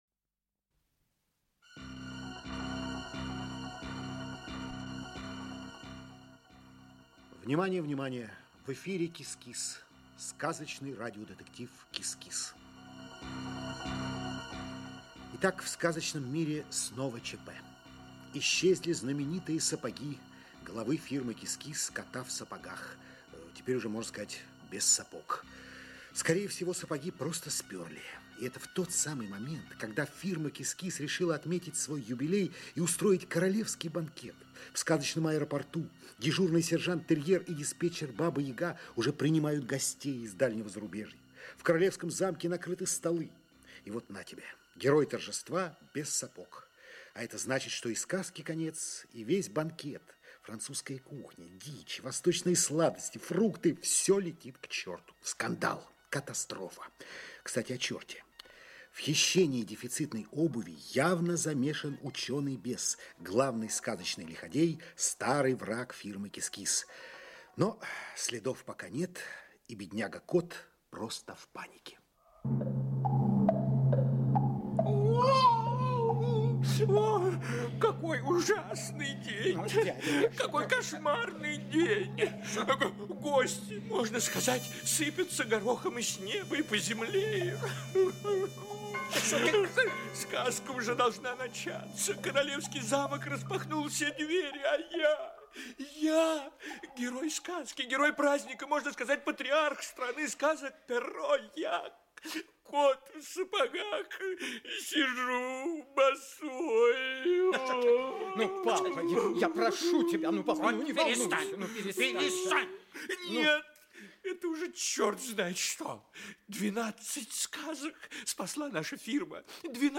Аудиокнига КИС-КИС. Дело № 13. Часть 2 | Библиотека аудиокниг